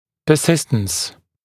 [pə’sɪstəns][пэ’систэнс]персистенция, перманентность, живучесть, сохраняемость, постоянство существования; настойчивость